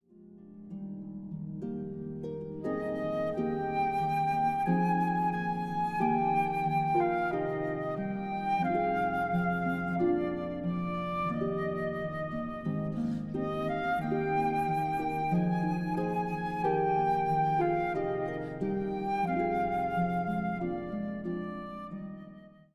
Een prachtige Cd met fluit-harp muziek.
Op de Cd zijn hymns te horen uit verschillende landen.